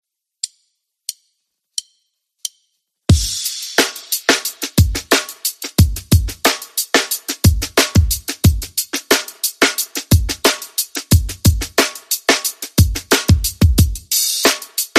Drum Loop
# drums # loop # percussion About this sound Drum Loop is a free music sound effect available for download in MP3 format.
052_drum_loop.mp3